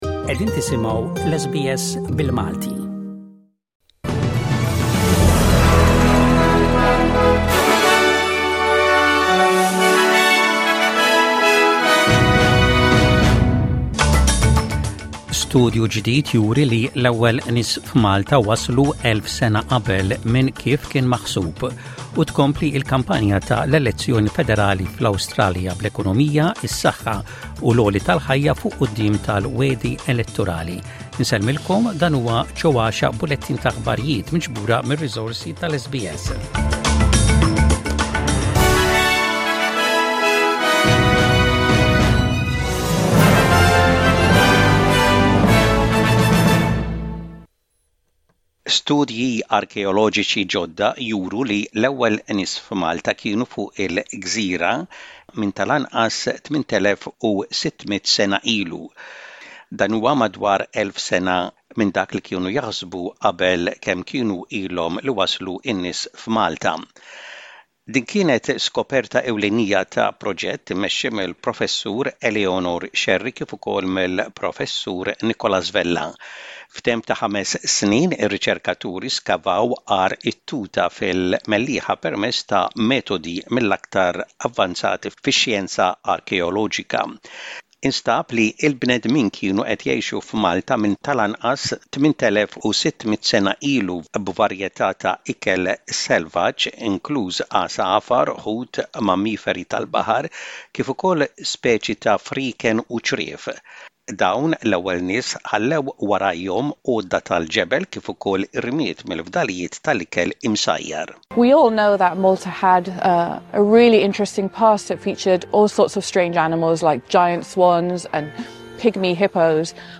Aħbarijiet bil-Malti: 11.04.25